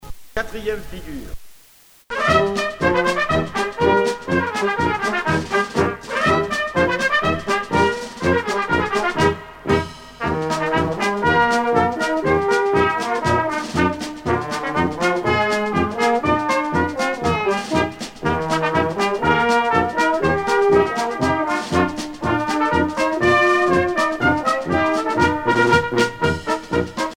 danse : quadrille
Pièce musicale éditée